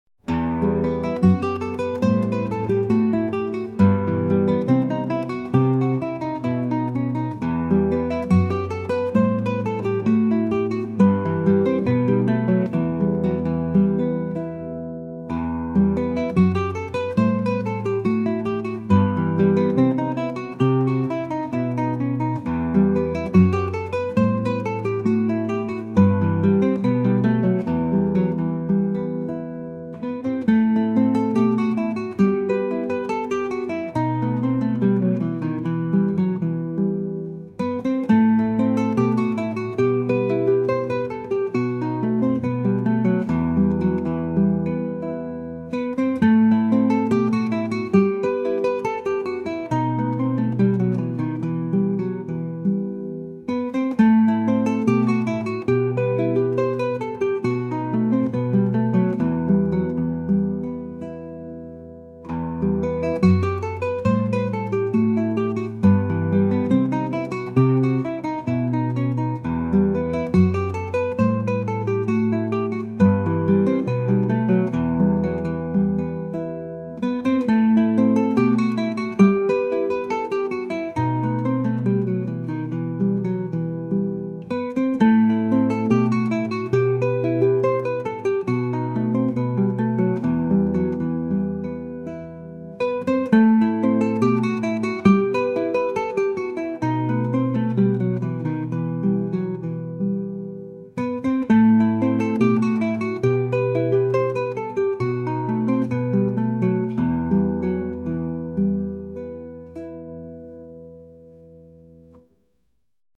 En esta página pueden escuchar algunos de los sonidos del musical flamenco «LA ÚLTIMA ESCLAVA DE GUADÍN».